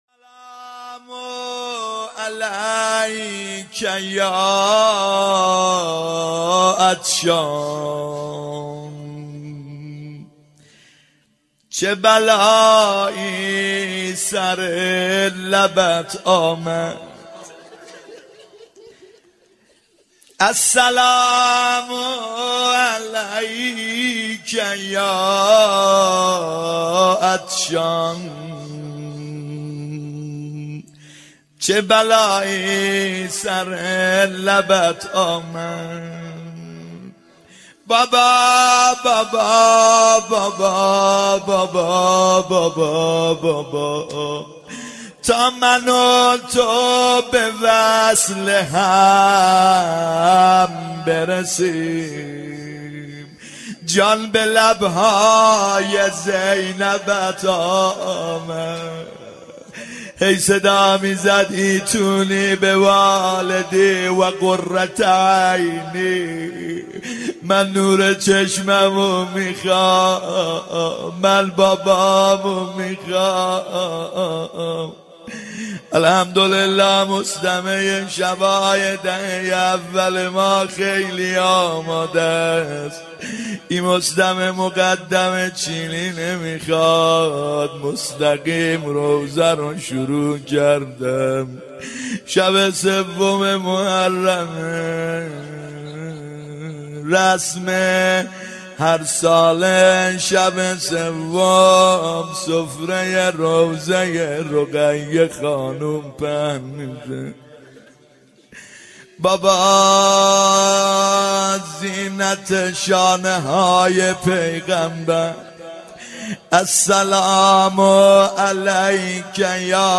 محرم 91 شب سوم روضه
محرم 91 ( هیأت یامهدی عج)